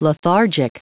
Prononciation du mot : lethargic